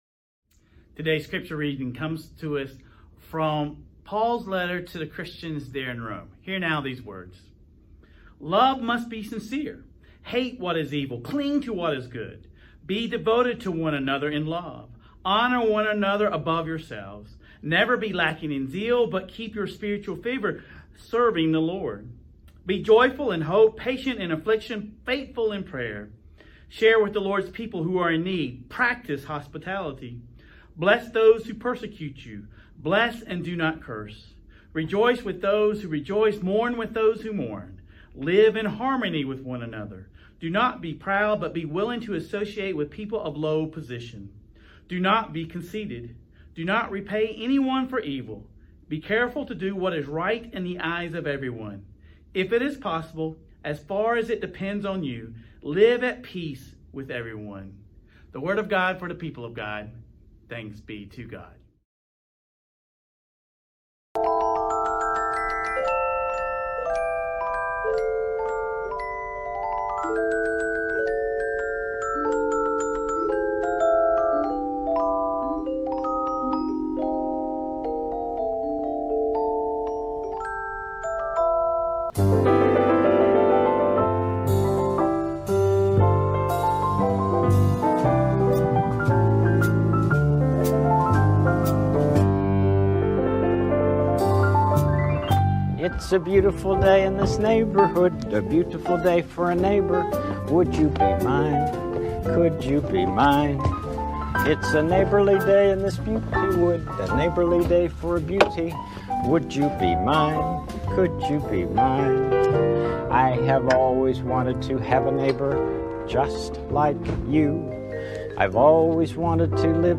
In this final installment, we explore the ways in which Mr. Rogers embodied our faith's call to live differently, as good neighbors and friends bridging the divides in our communities. Sermon Reflections: How does the sermon challenge traditional notions of "neighbor" and encourage us to expand our understanding of who we are called to love?